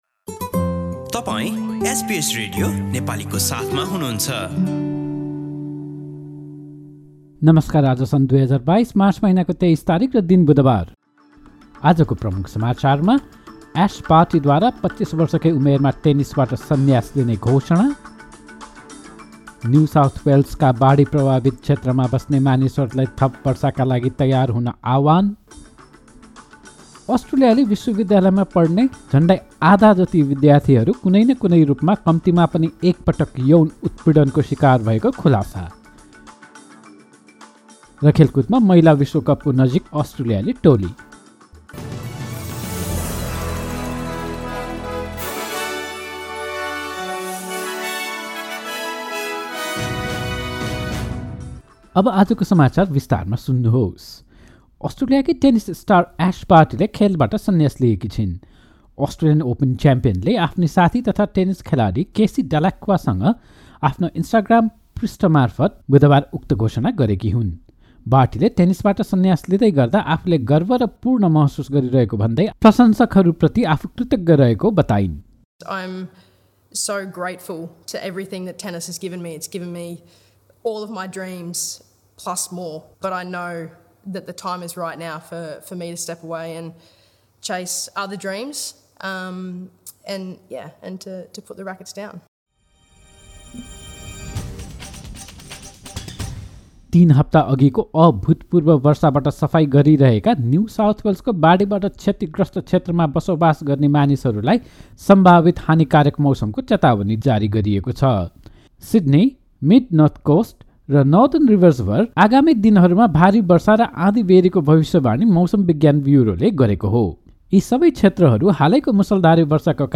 एसबीएस नेपाली अस्ट्रेलिया समाचार: बुधवार २३ मार्च २०२२